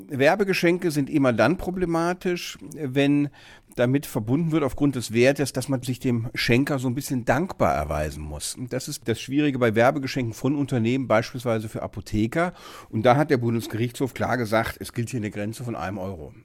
O-Ton + Kollegengespräch: Keine Werbegeschenke von Pharmaunternehmen an Apotheken über ein Euro